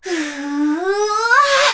Kara-Vox_Casting4.wav